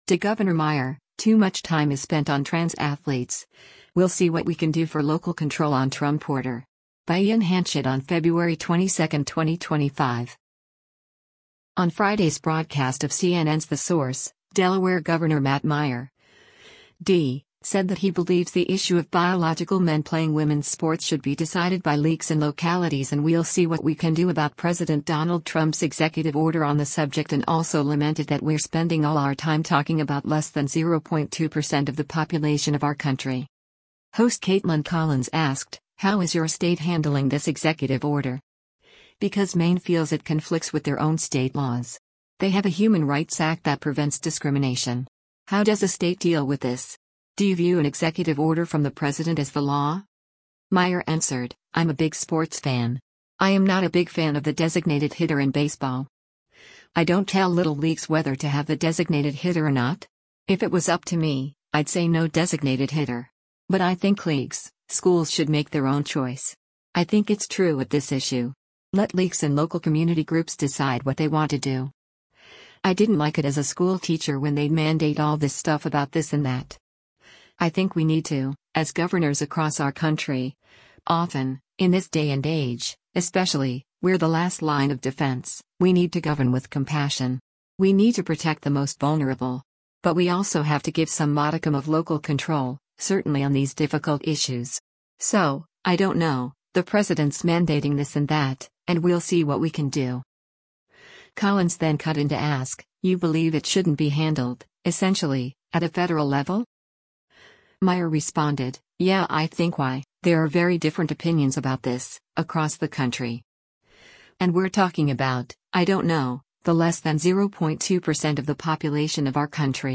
On Friday’s broadcast of CNN’s “The Source,” Delaware Gov. Matt Meyer (D) said that he believes the issue of biological men playing women’s sports should be decided by leagues and localities and “we’ll see what we can do” about President Donald Trump’s executive order on the subject and also lamented that “We’re spending all our time talking about” “less than 0.2 percent of the population of our country.”